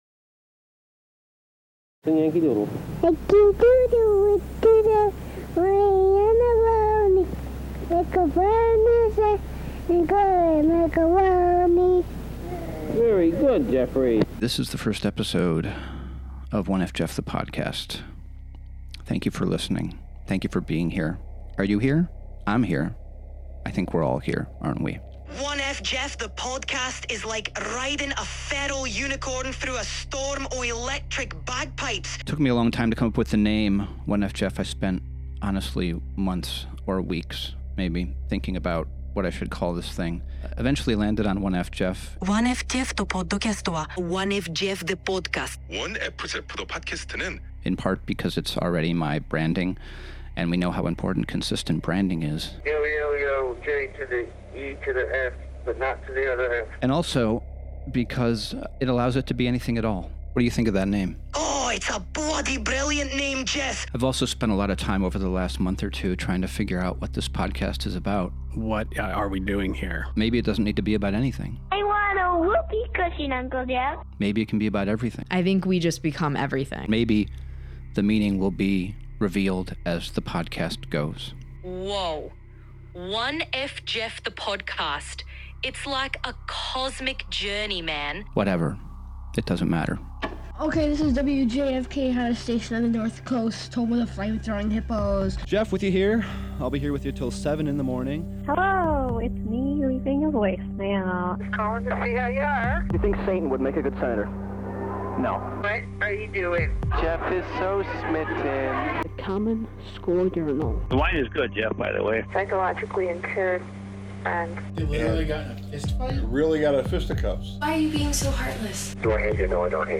It's a recording of a story I told at the Bowery Poetry Club in New York City back in 2014, and I hope you'll enjoy it.